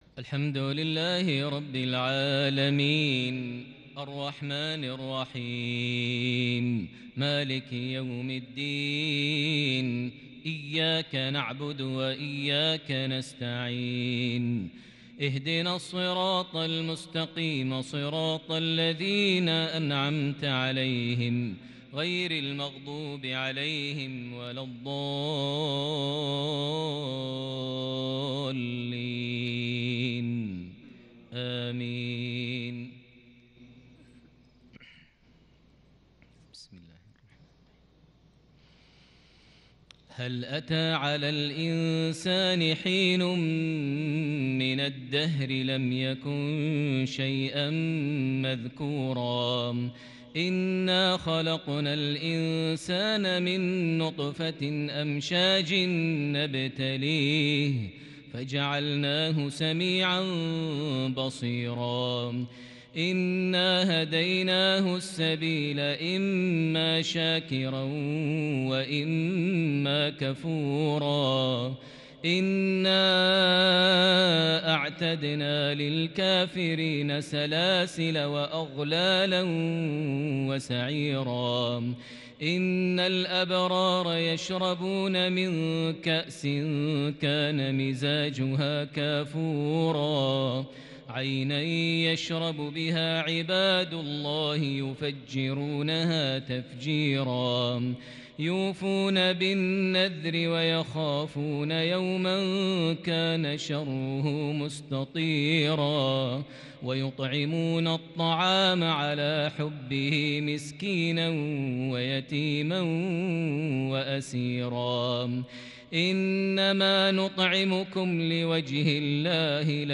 تلاوة تحبيرية متقنة من سورة الإنسان (1-22) | عشاء 21 محرم 1442هـ > 1442 هـ > الفروض - تلاوات ماهر المعيقلي